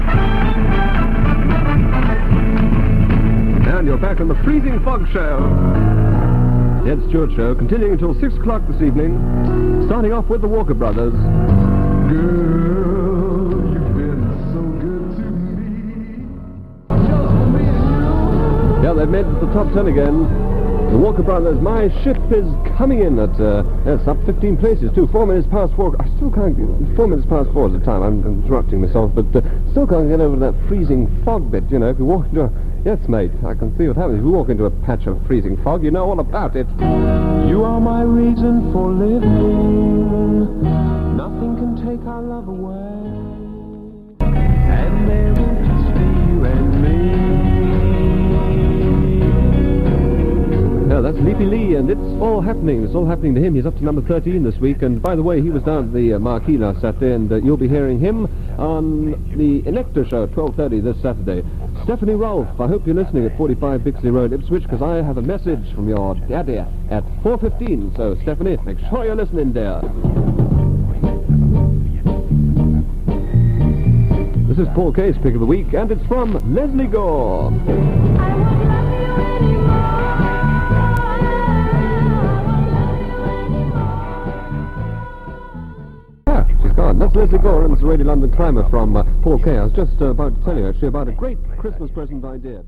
click to hear audio Ed Stewart on his 3-6pm show, 7th December 1965.